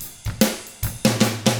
146ROCK F3-L.wav